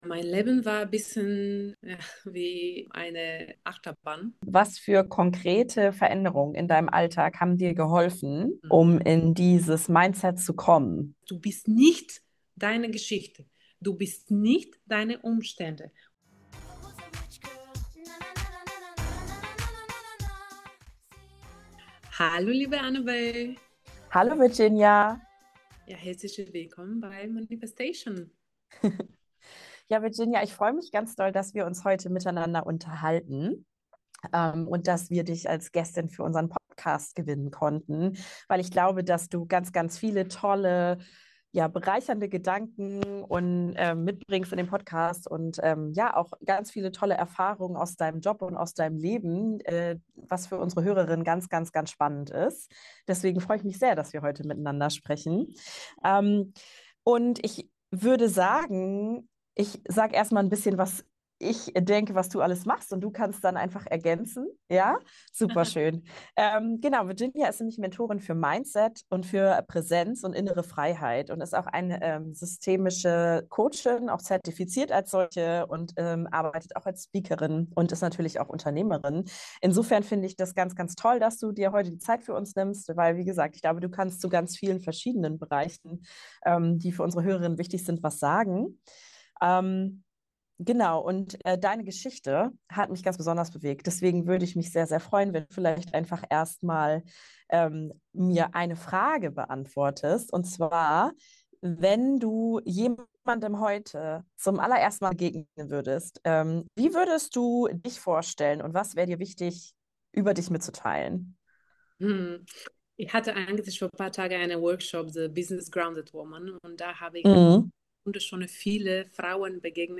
Wie kreierst du ein starkes Mindset? Gespräch